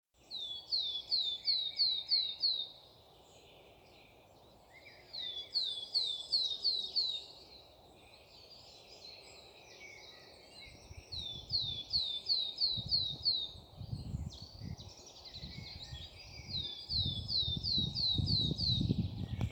Birds -> Tits ->
Willow Tit, Poecile montanus
StatusSinging male in breeding season